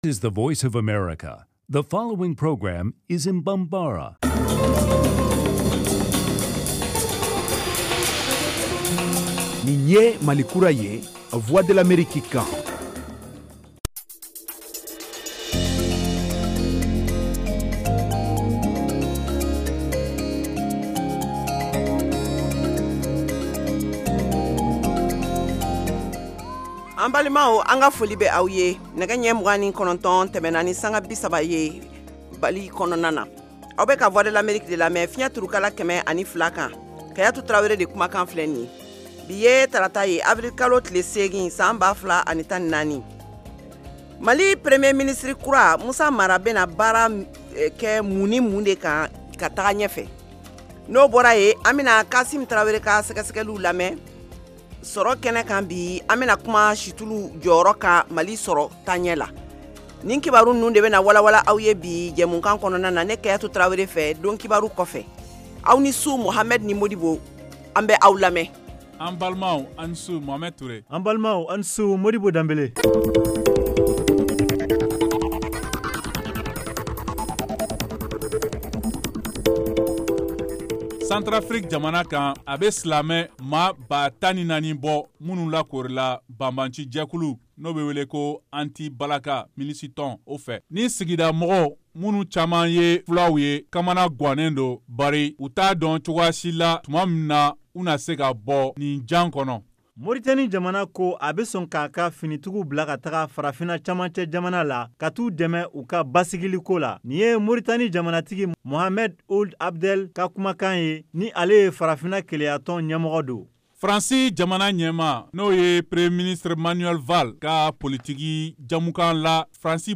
Emission quotidienne
en direct de Washington. Au menu : les nouvelles du Mali, les analyses, le sport et de l’humour.